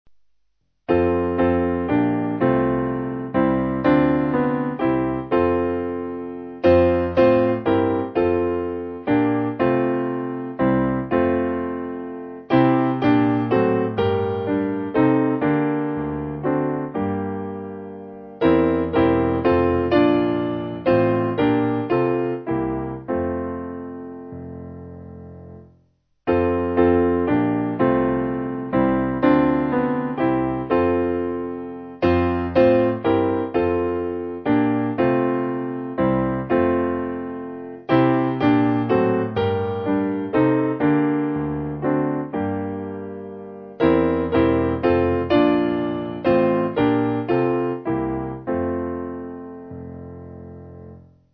Simple Piano